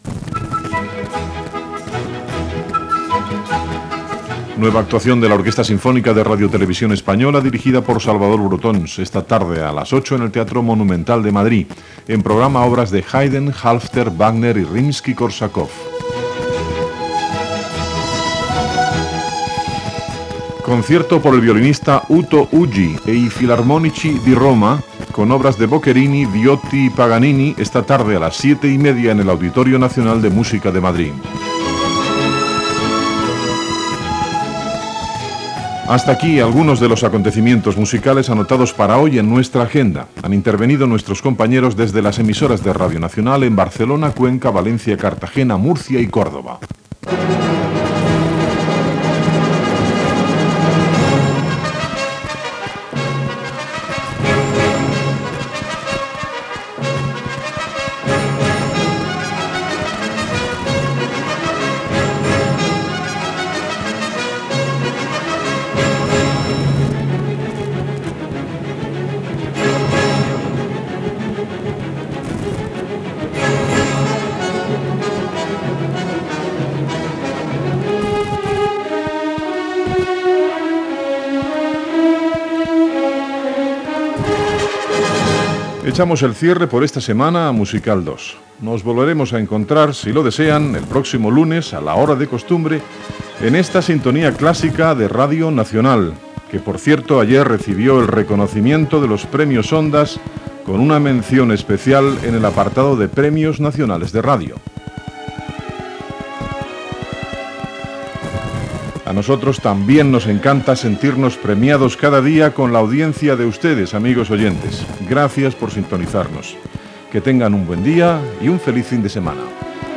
Agenda i comiat del programa "Musical 2". Indicatiu de l'emissora del 30è aniversari. Inici del programa "Álbum de discos".
FM